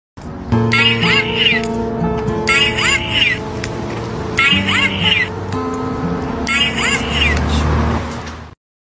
"I love you" doll (orig sound effects free download